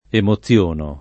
emoziono [ emo ZZL1 no ]